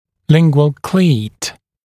[‘lɪŋgwəl kliːt][‘лингуэл кли:т]зацеп, находящийся на лингвальной стороне бандажного кольца